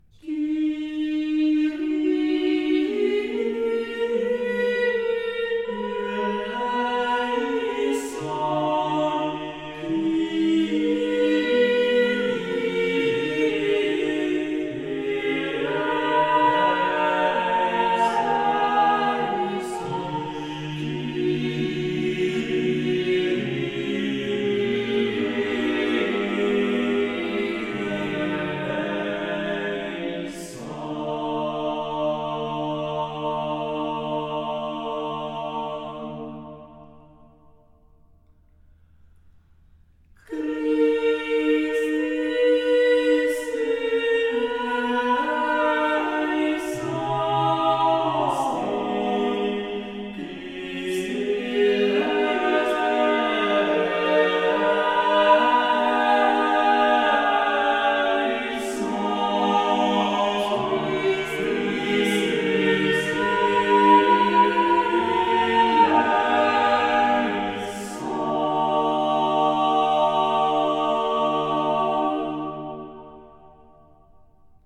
Missa cuiusvis toni Kyrie in Re (в первом тоне)